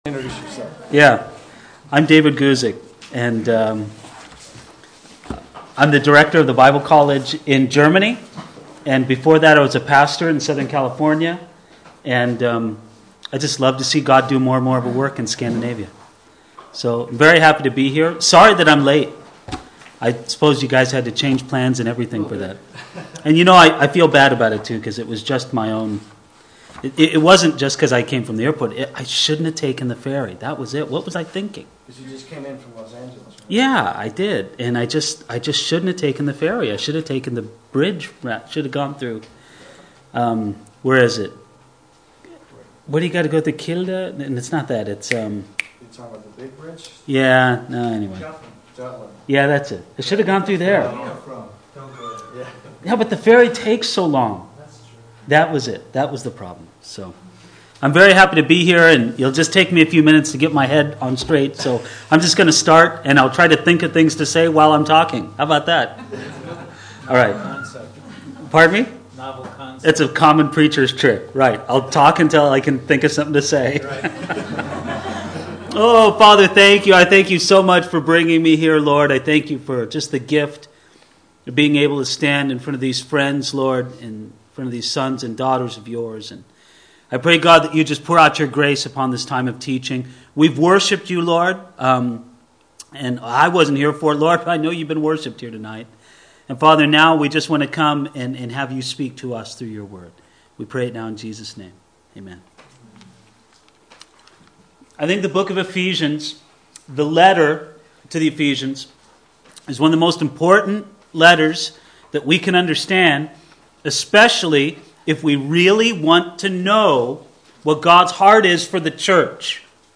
Conference 2007 Just like 2004 we were now back at "kilden" in Denmark.